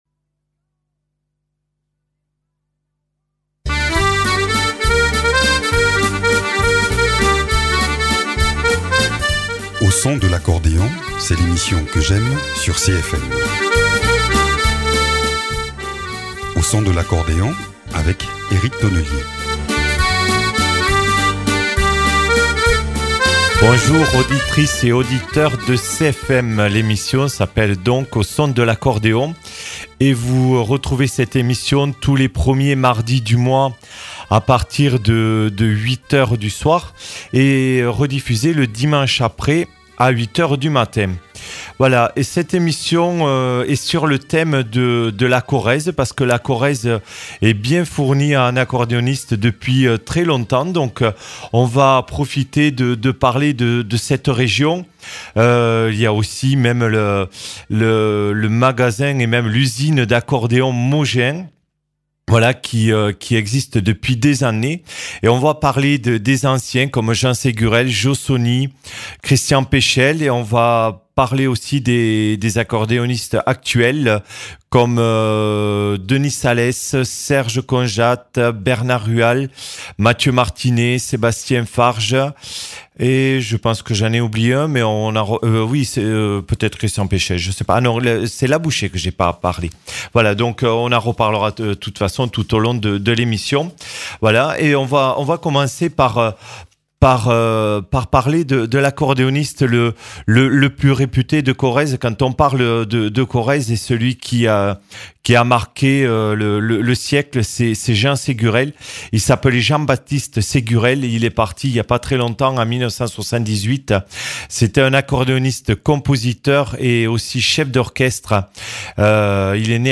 Au son de l’accordéon